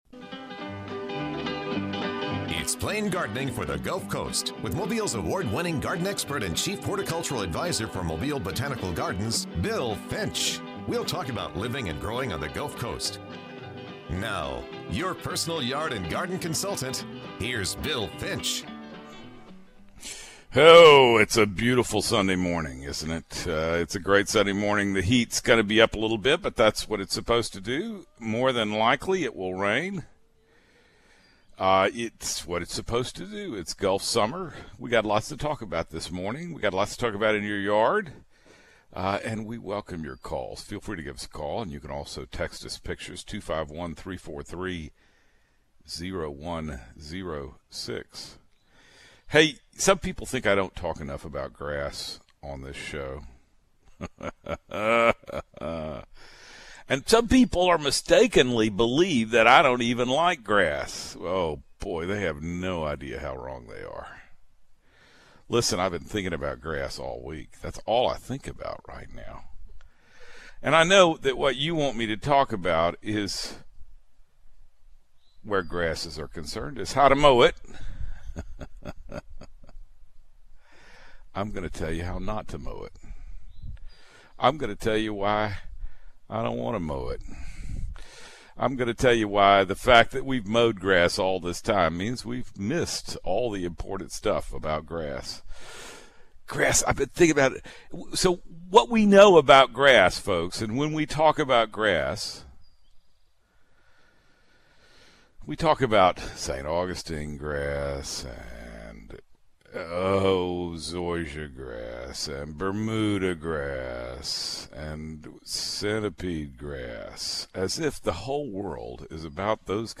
weekly gulf coast gardening show